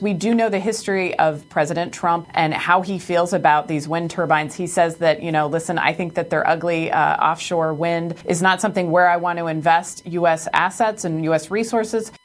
December 24th, 2025 by WCBC Radio